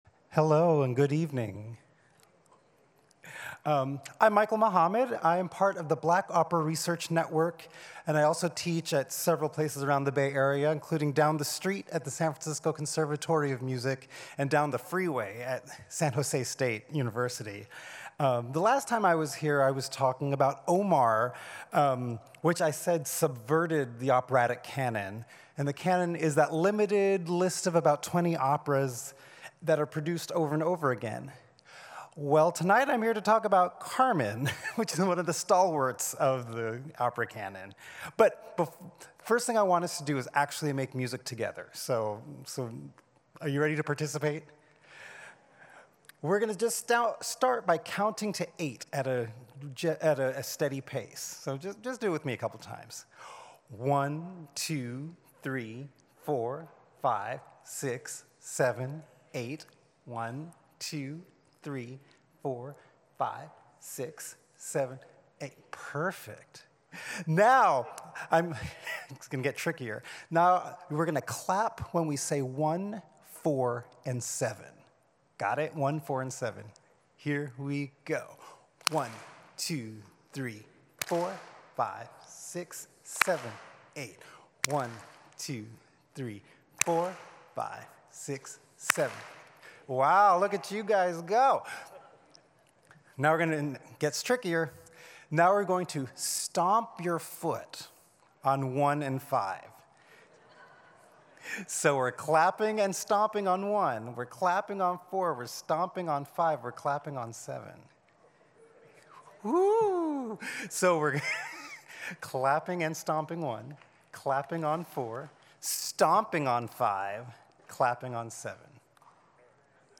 carmen_pre-show_lecture.mp3